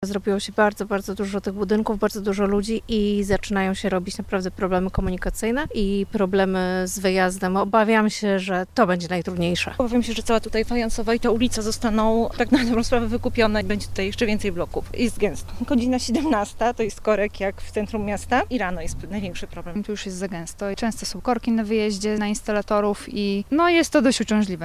Kolejne bloki mogą doprowadzić do paraliżu – skarżą się lokatorzy osiedla.